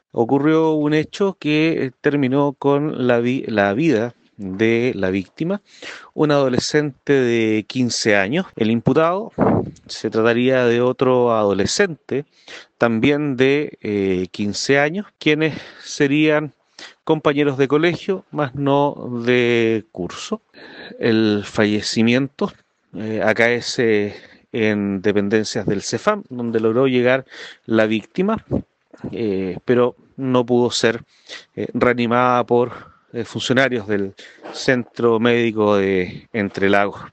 El fiscal Narciso García, de la Fiscalía Local de Osorno, informó los hechos que mantienen detenido a un joven de 15 años de edad, quien compartía colegio con el menor fallecido.